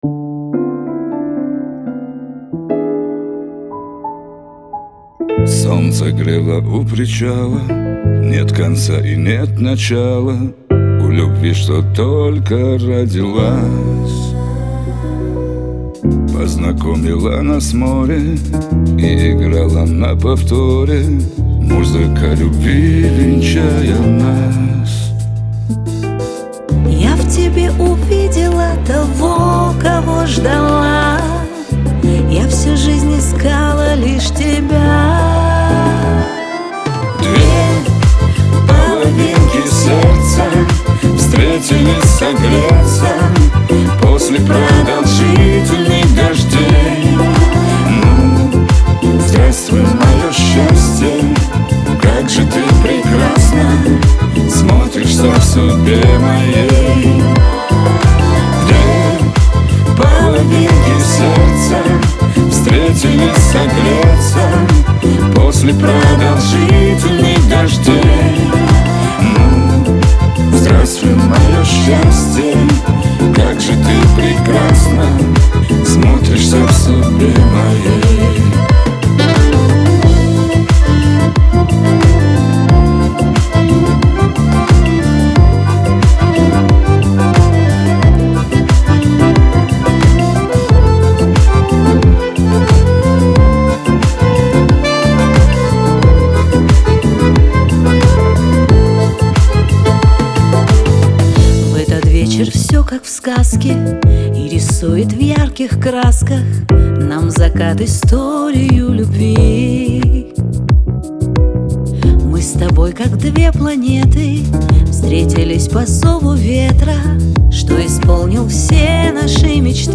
дуэт
pop , диско
эстрада